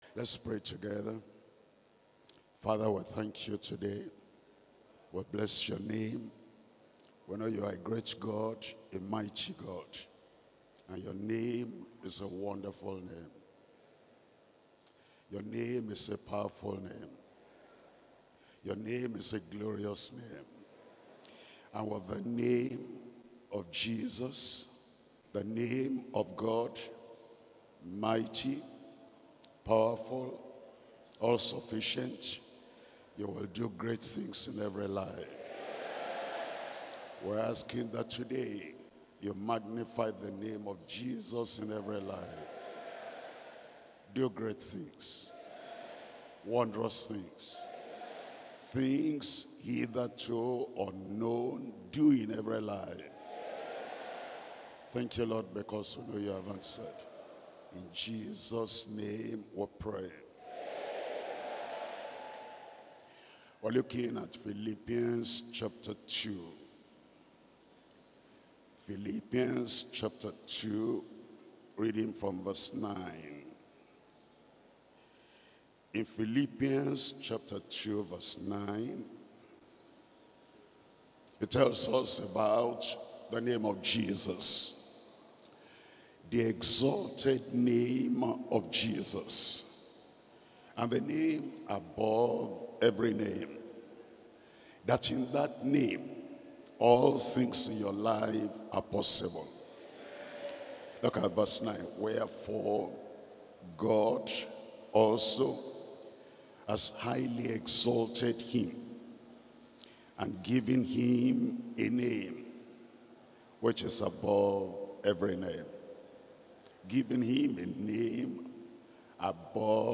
Sermons - Deeper Christian Life Ministry
2025 Global December Retreat